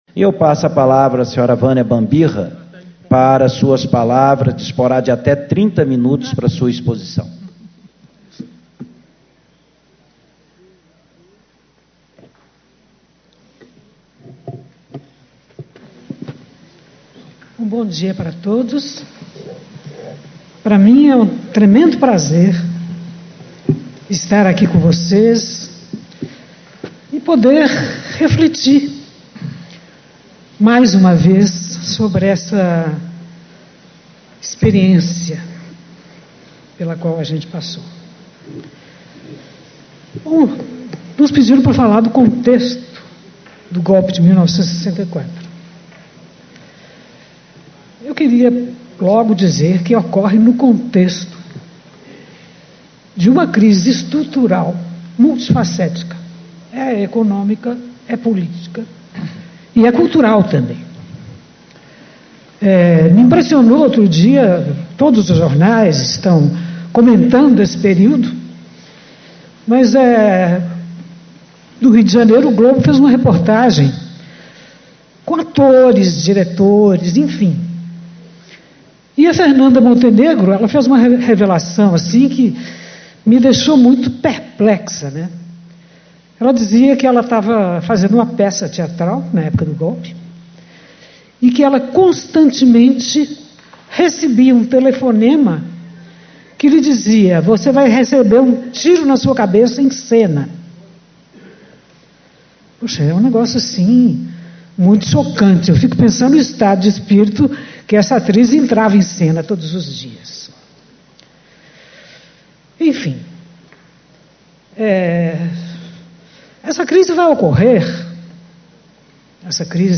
Ciclo de Debates 50 Anos do Golpe Militar de 1964
Discursos e Palestras